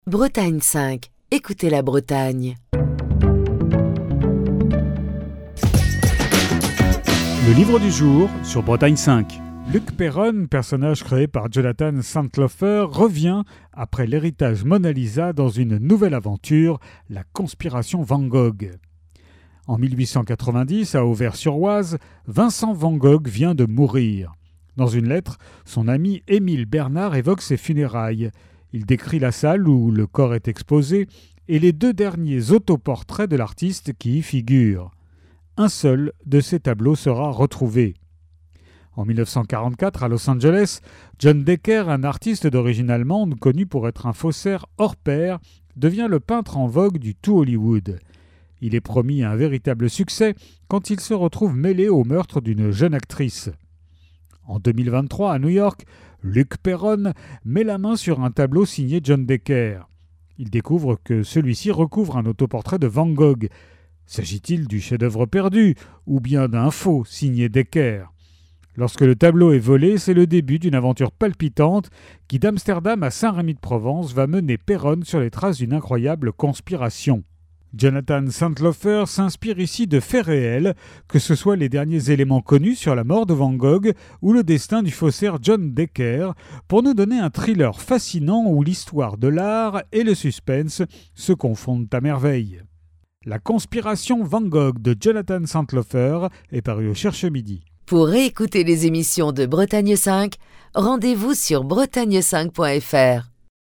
Chronique du 14 novembre 2024.